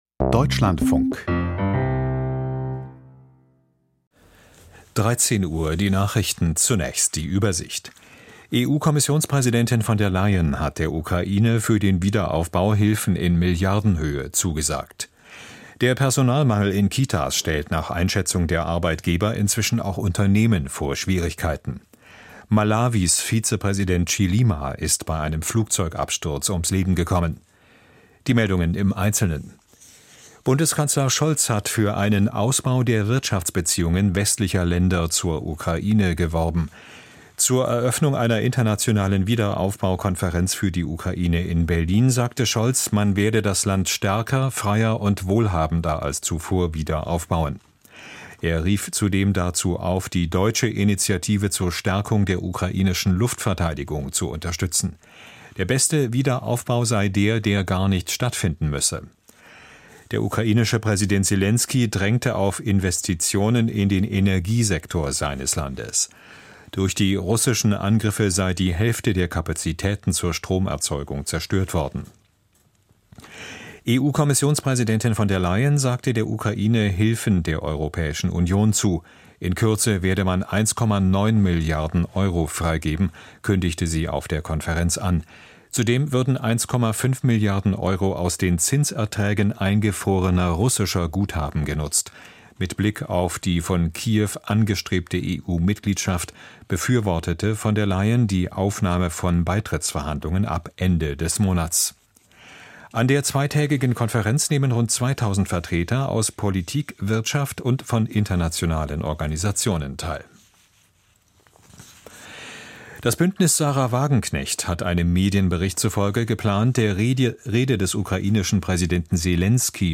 Nahost-Friedensplan im Sicherheitsrat: Interview mit Kerstin Müller, DGAP - 11.06.2024